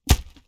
Close Combat Attack Sound 5.wav